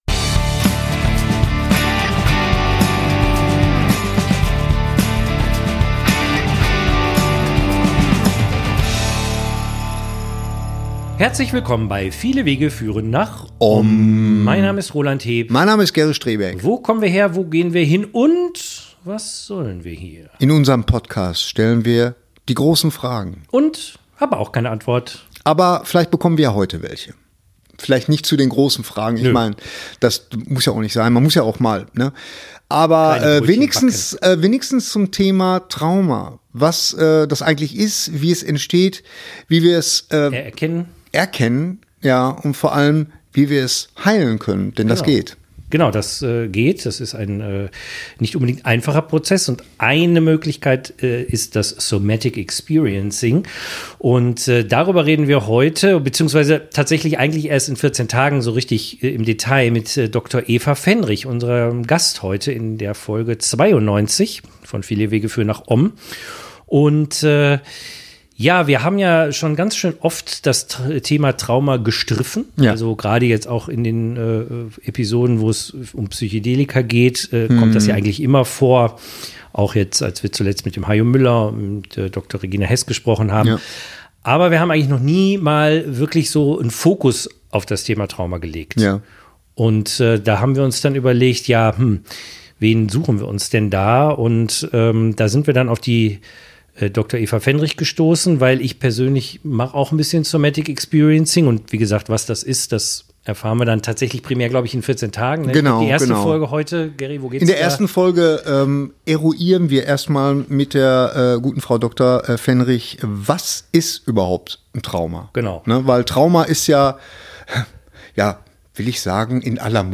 Was ist ein Trauma, wie entsteht es und wie kann man es auflösen? Diese und vieles interessantes mehr erfahrt ihr in diesem ersten Teil dieses spannenden Interviews.